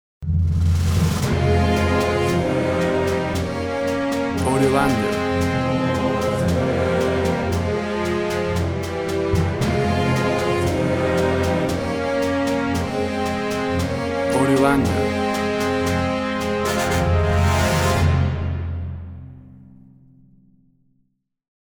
WAV Sample Rate 24-Bit Stereo, 44.1 kHz
Tempo (BPM) 115